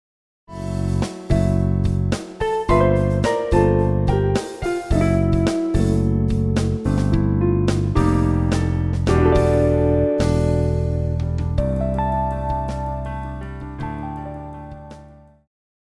流行
鋼琴
樂團
鋼琴曲,演奏曲
搖滾
獨奏與伴奏
沒有節拍器